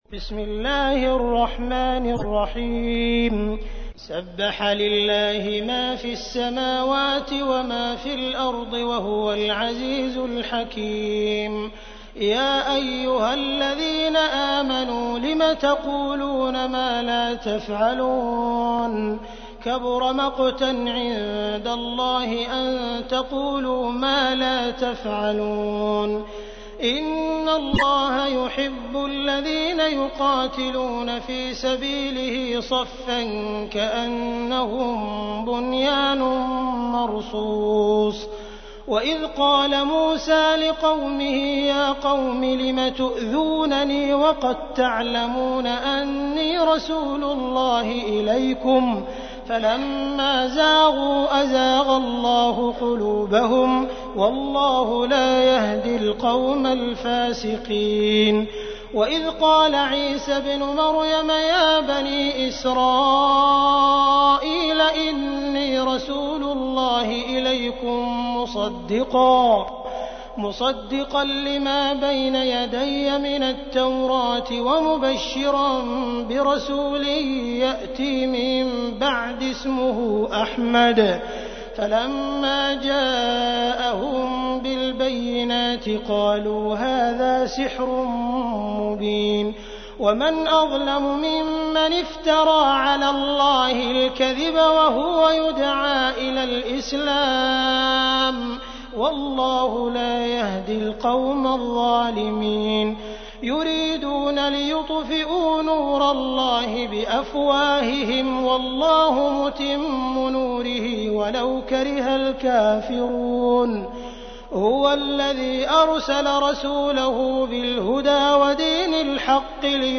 تحميل : 61. سورة الصف / القارئ عبد الرحمن السديس / القرآن الكريم / موقع يا حسين